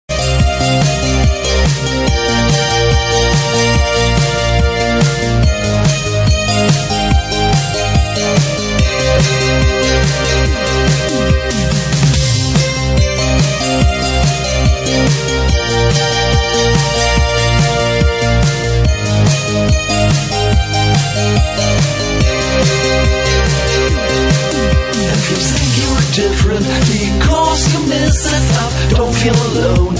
[Lo-Fi preview] Remixers Website